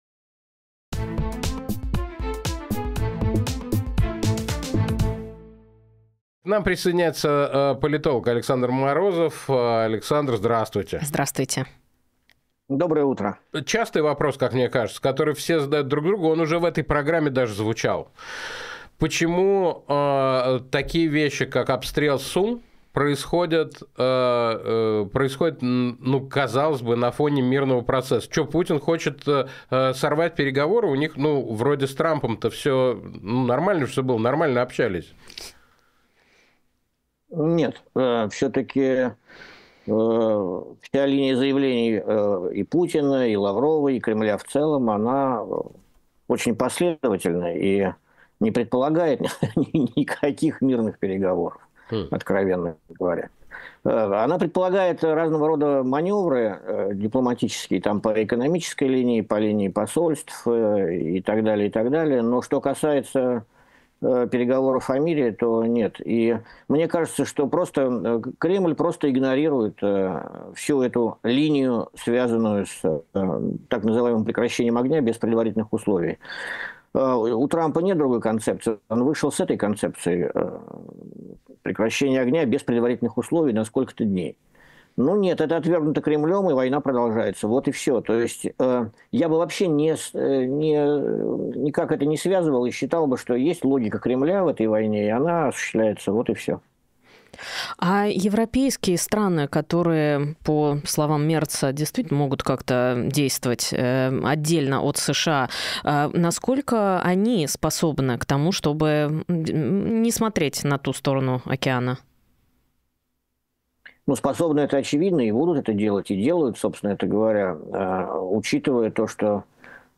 Фрагмент эфира от 14.04